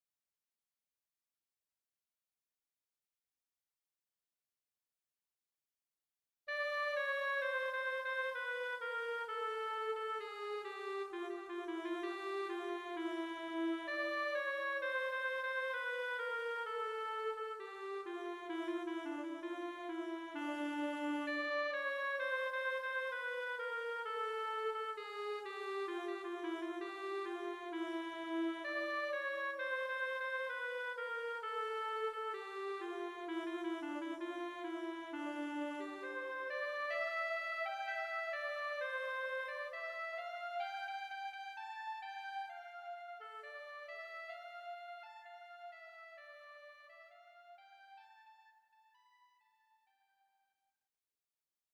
：B-MIDIでメロディをひろい、歌いながら発音の練習をします。
テンポ オリジナル
midi_original.mp3